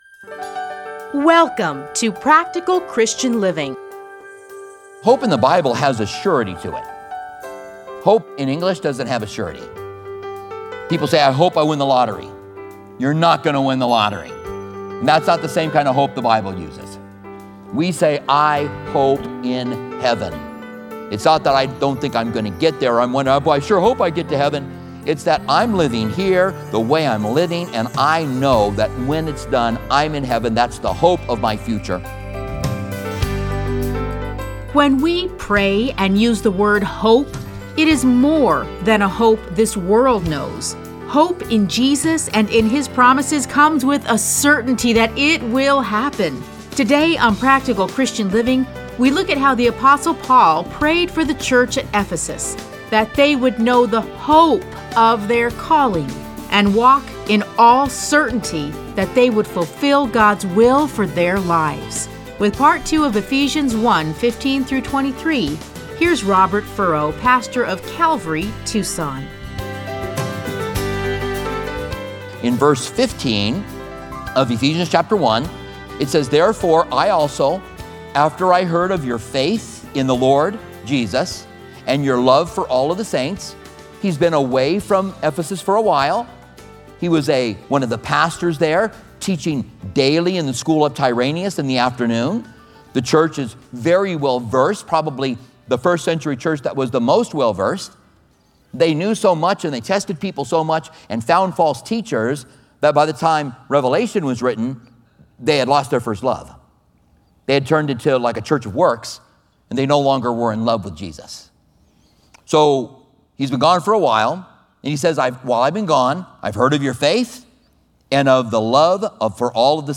Listen to a teaching from Ephesians 1:15-23.